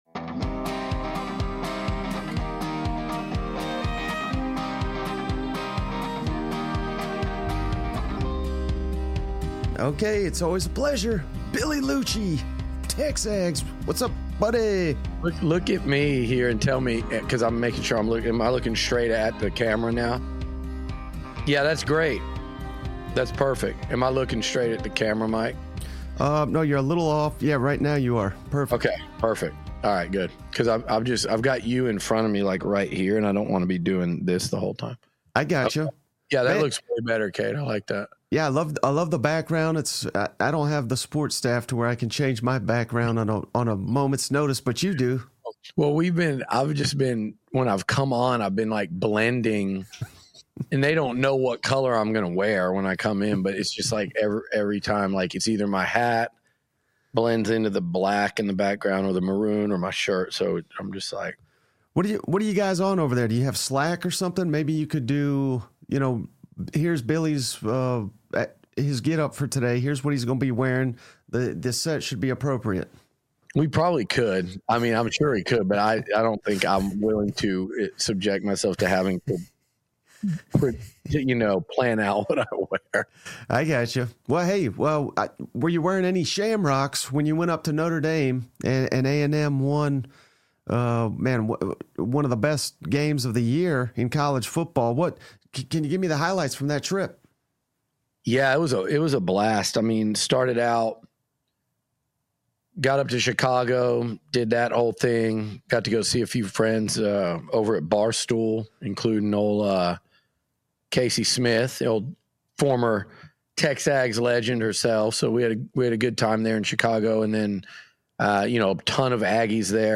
interview!